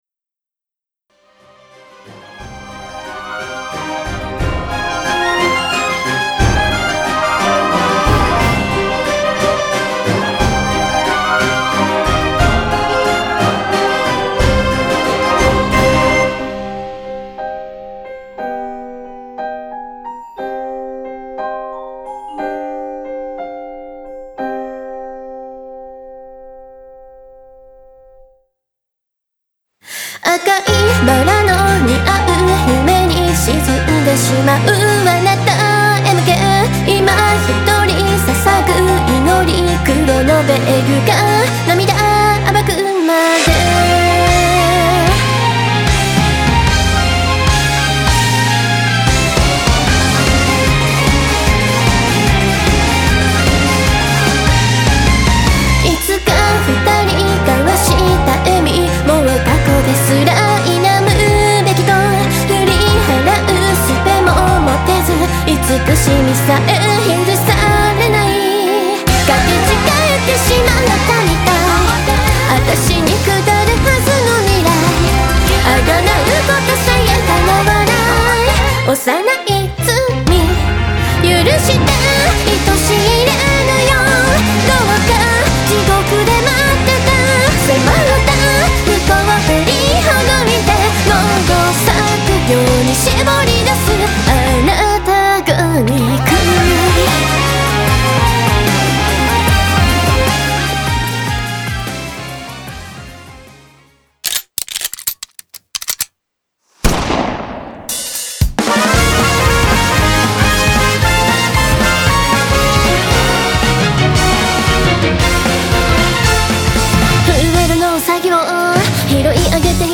- Instrumental Version -